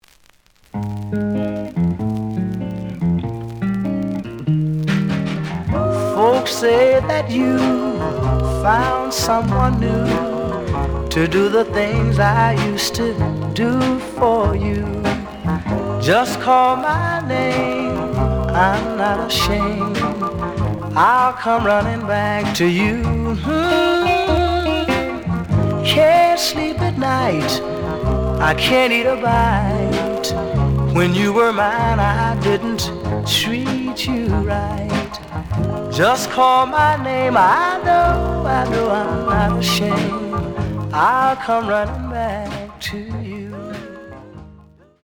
試聴は実際のレコードから録音しています。
●Genre: Rhythm And Blues / Rock 'n' Roll